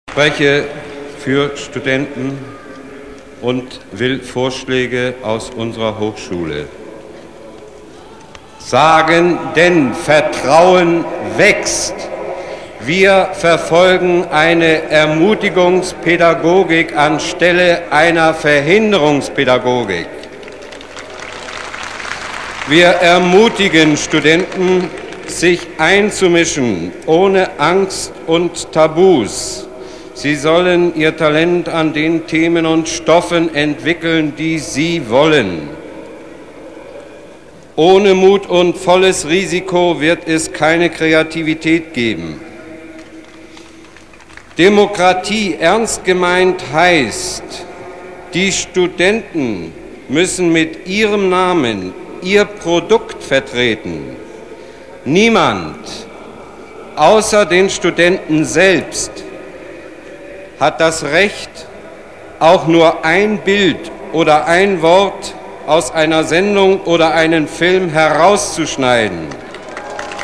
Reden vom 4.11.1989-Lothar Byski